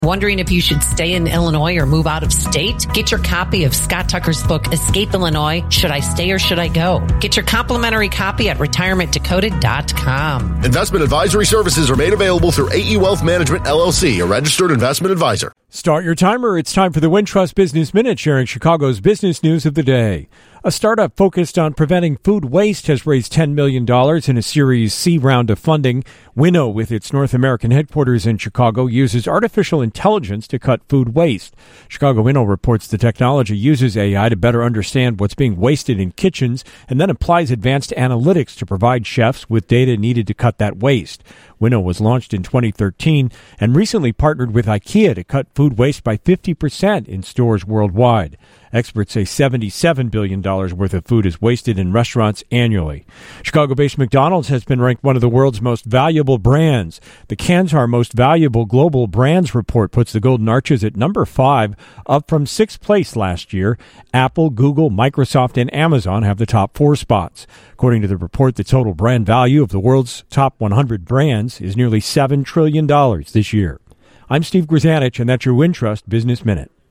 business news of the day